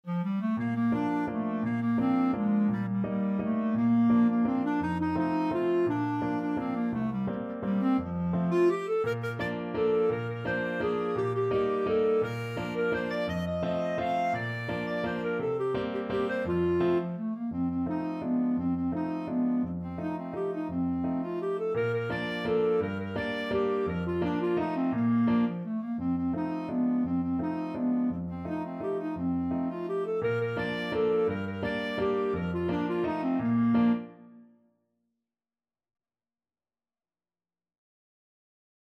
Classical Schubert, Franz Cotillon D.976 Clarinet version
Clarinet
Bb major (Sounding Pitch) C major (Clarinet in Bb) (View more Bb major Music for Clarinet )
3/4 (View more 3/4 Music)
Classical (View more Classical Clarinet Music)